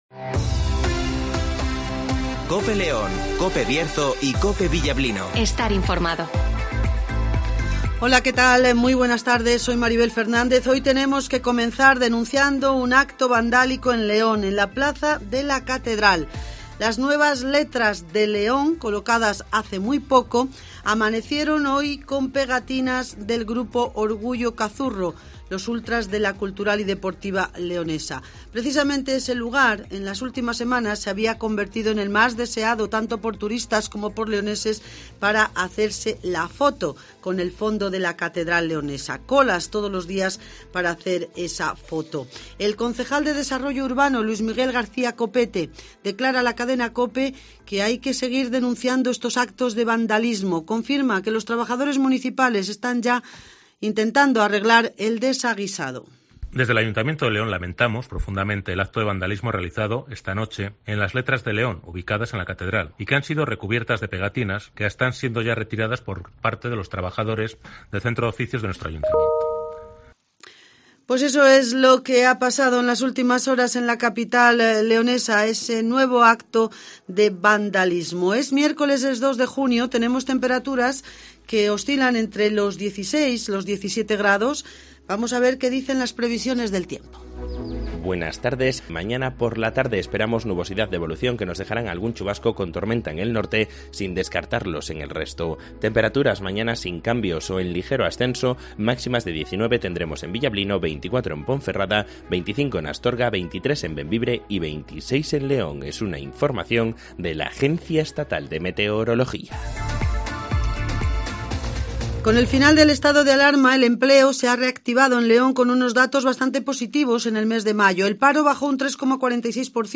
-Deportes